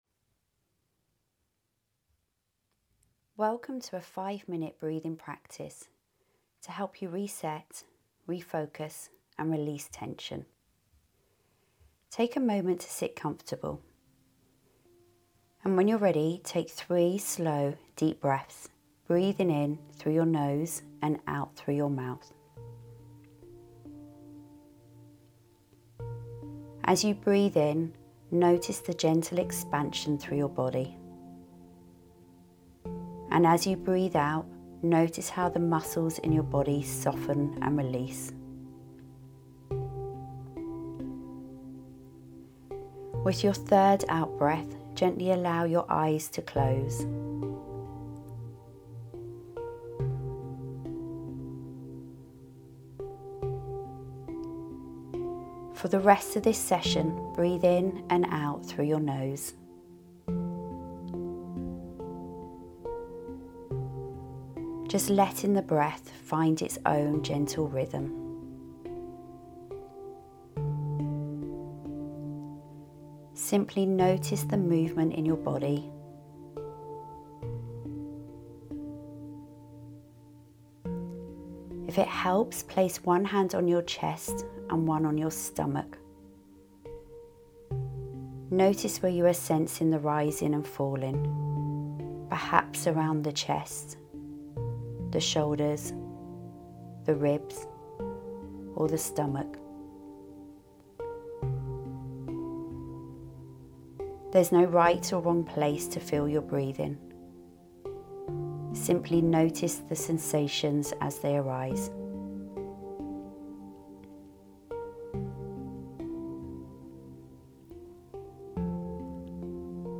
Over the next five minutes, you’ll be guided through a simple breathing practice designed to calm your nervous system and help you return to your day with greater clarity and ease.